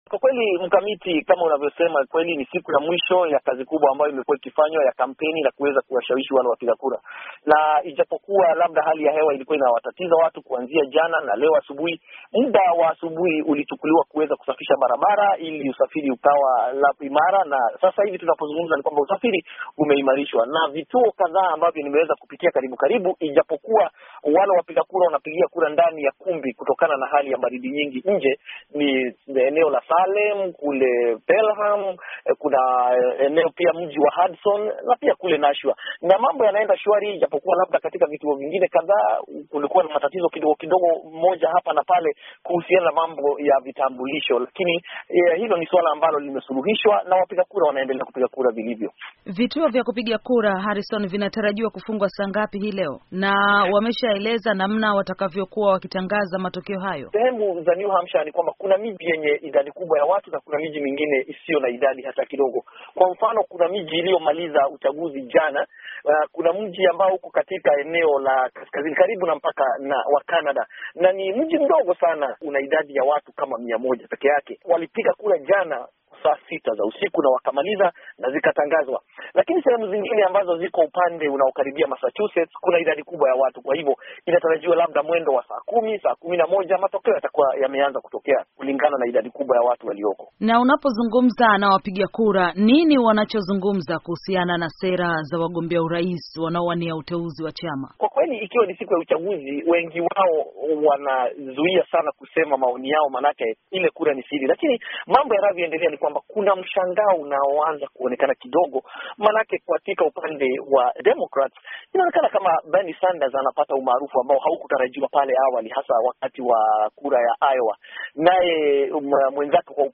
Mahojiano ya VOA